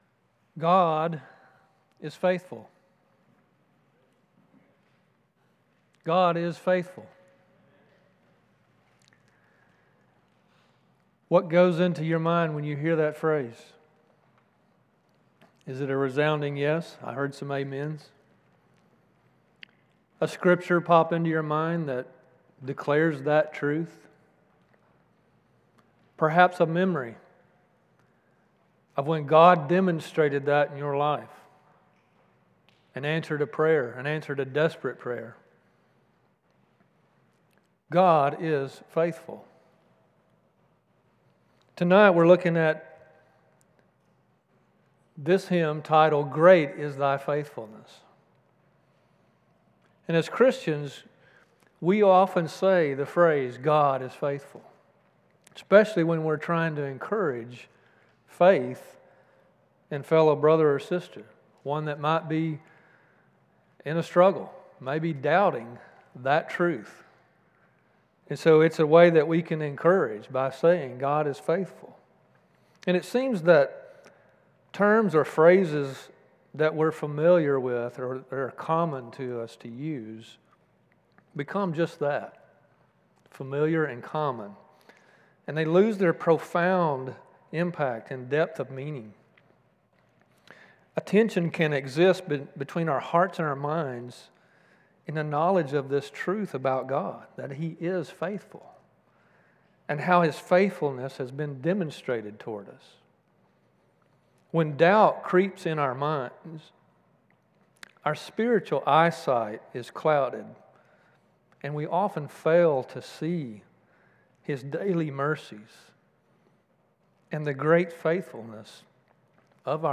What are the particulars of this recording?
This message was delivered on Sunday, November 2nd, 2025, at Chaffee Crossing Baptist Church in Barling, AR.